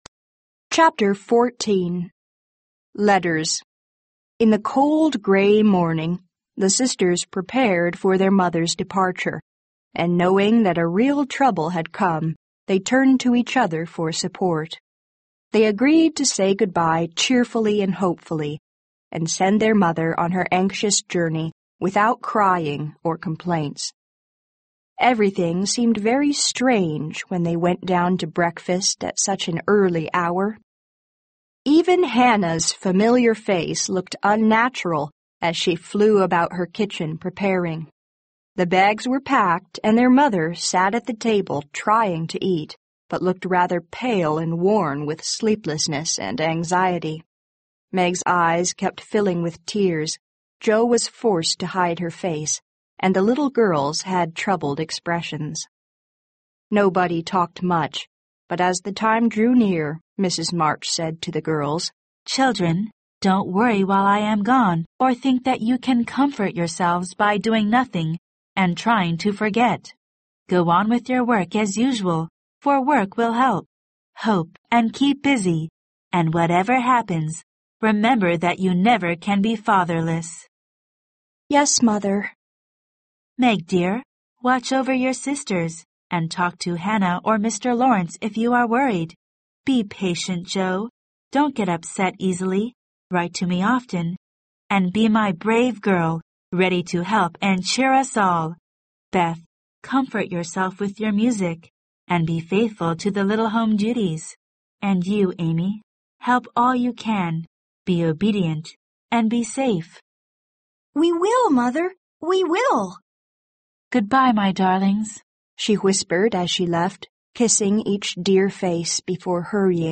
有声名著之小妇人 14 听力文件下载—在线英语听力室